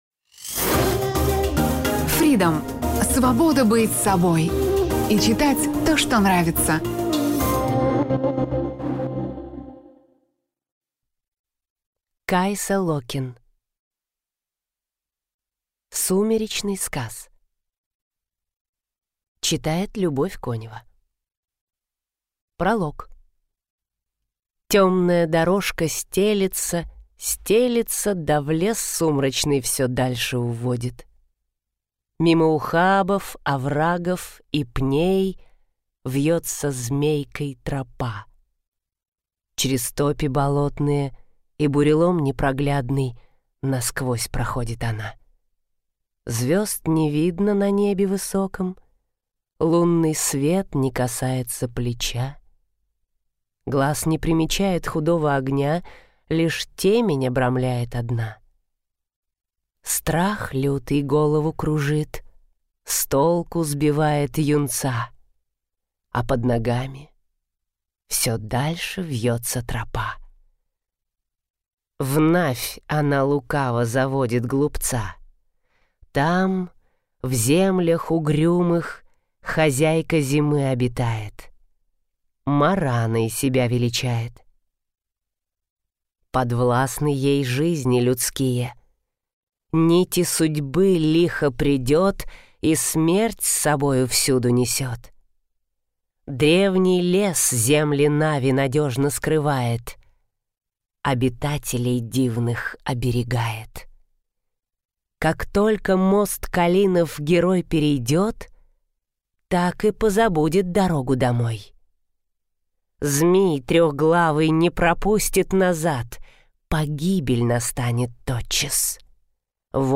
Аудиокнига Сумеречный сказ | Библиотека аудиокниг